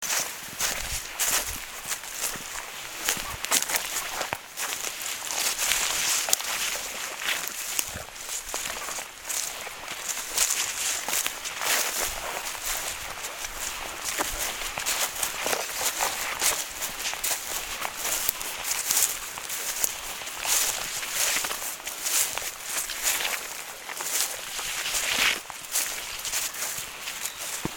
I stop and listen and decide to do sound takes for my fieldwork right now. I hear only the sound of the light breeze passing my body.
The leaves rustle. I put the recorder next to the bush to get a better sound take.
Some slow and some fast rustling depending on the speed of the wind’s gust.
The mountain boots are hitting the sand with a thump  sound. There’s a lighter pitch to the sound as I knock my toe and stumble on a big rock on the path. I’m finally walking into the soundscape of the Finnmark tundra.
Gå-i-lyng-og-buskas.mp3